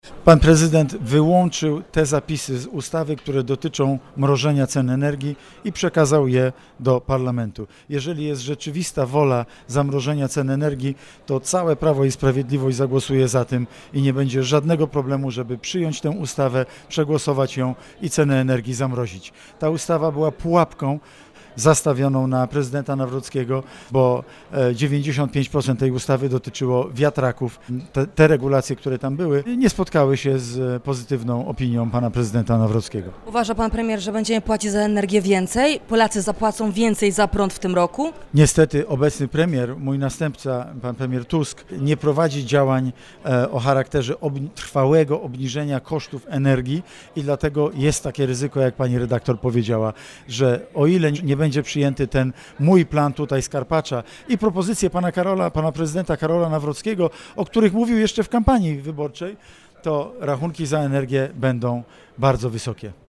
Zapytaliśmy także naszego „Porannego Gościa” o zawetowanie ustawy wiatrakowej z zapisami o mrożeniu cen energii i czy zapłacimy więcej za prąd. -Jest ryzyko, że jeśli nie zostanie przyjęty mój plan obniżenia energii z Karpacza, a także propozycje prezydenta Karola Nawrockiego, to rachunki za energię będą bardzo wysokie – mówi Mateusz Morawiecki.